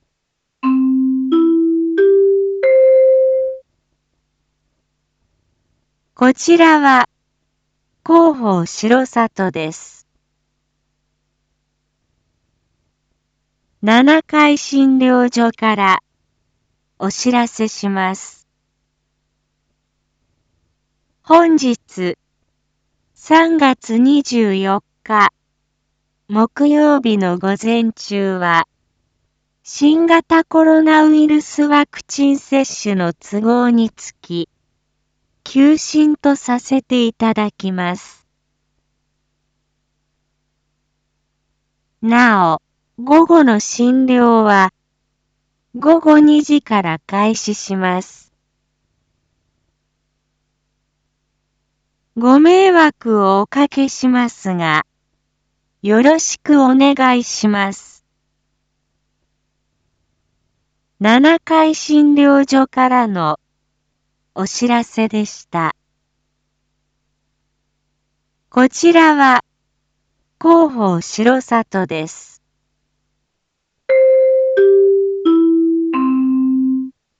Back Home 一般放送情報 音声放送 再生 一般放送情報 登録日時：2022-03-24 07:01:19 タイトル：R4.3.24 7時 放送分 インフォメーション：こちらは広報しろさとです。